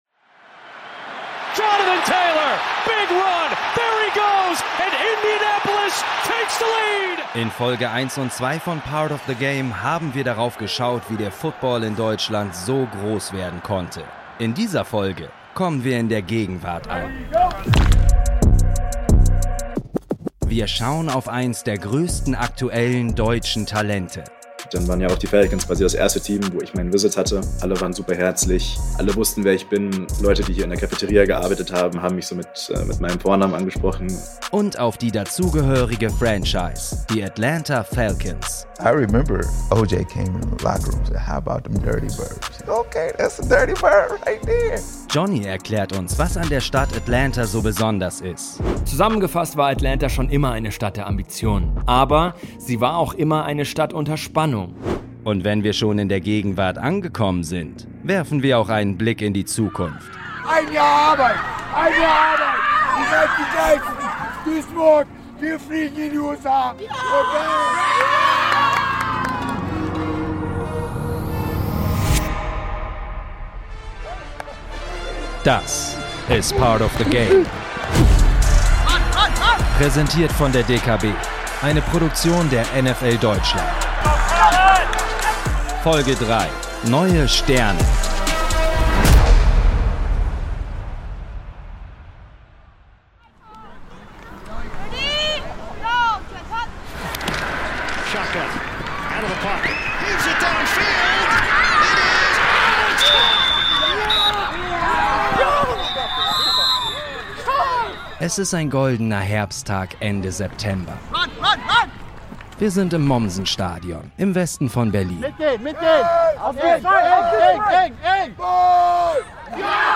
Touchdown! Emotionale Jubelschreie hallen durchs Stadion.